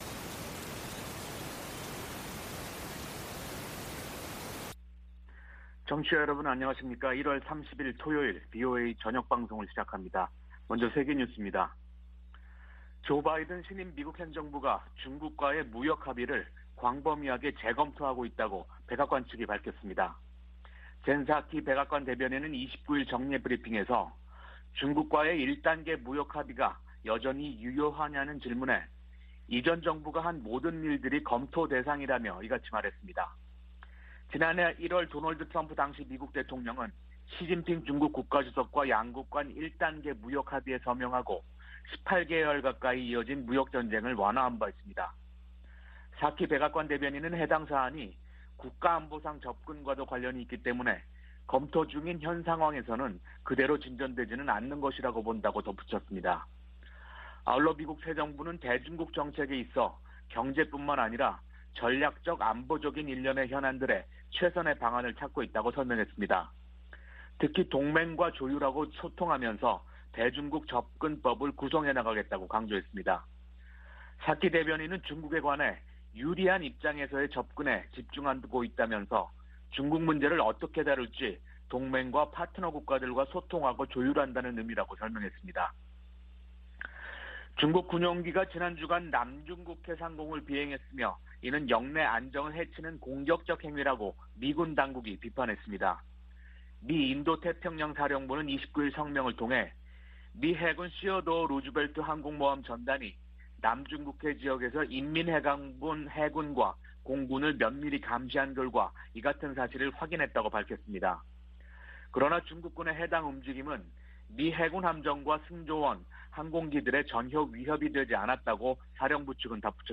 VOA 한국어 방송의 토요일 오후 프로그램 1부입니다.